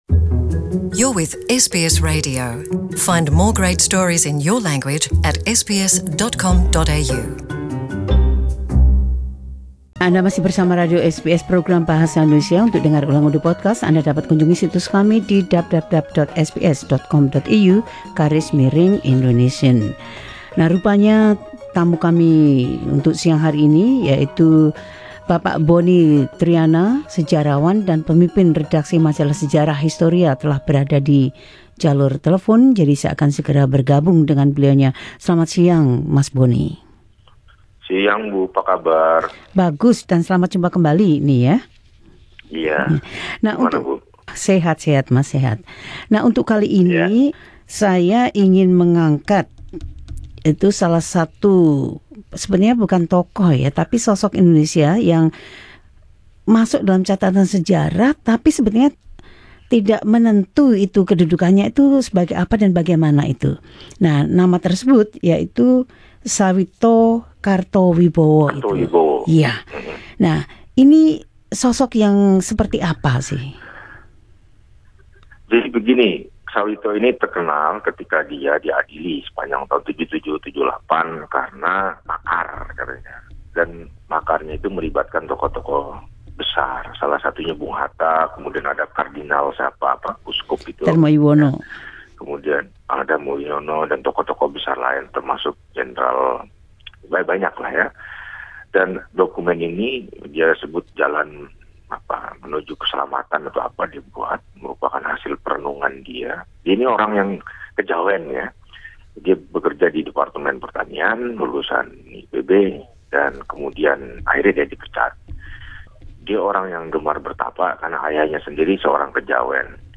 Bonnie Triyana, sejarawan dan pemimpin redaksi majalah Histori, berbicara tentang dampak dari kasus Sawito Kartowibowo serta relevansinya terhadap Indonesia kini.